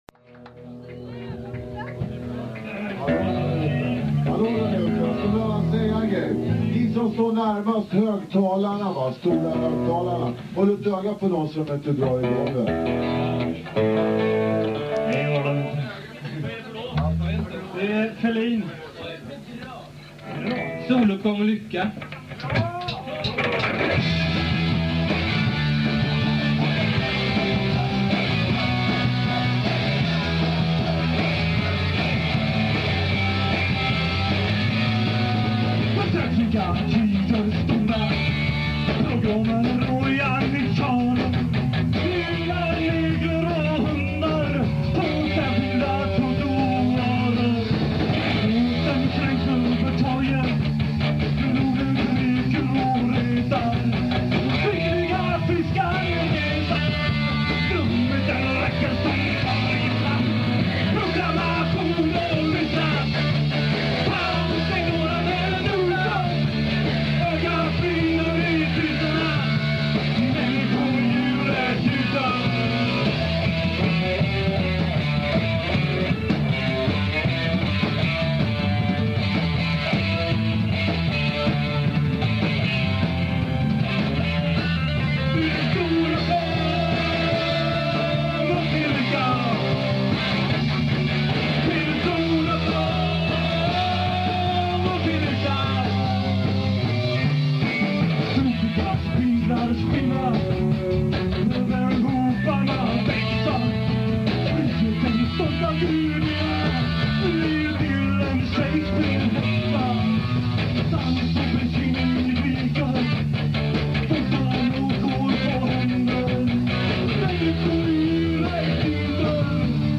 Total mani, total rock.